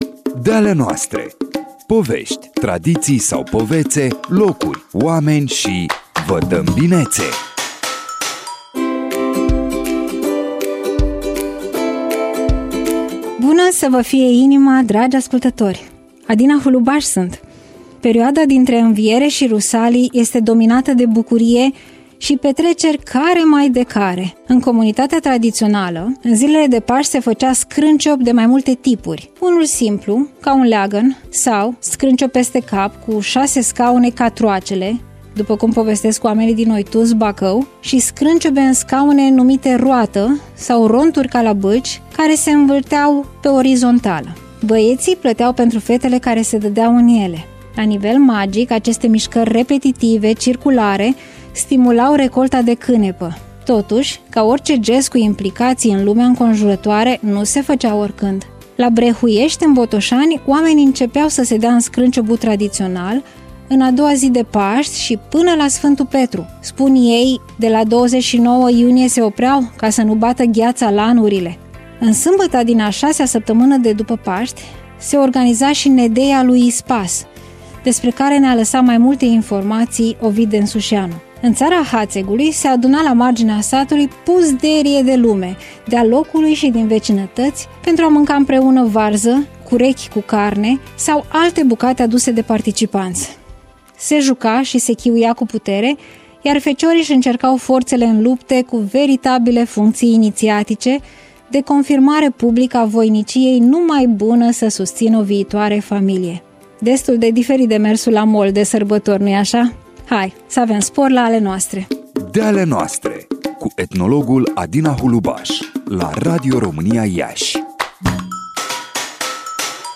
pe frecvențele Radio România Iași: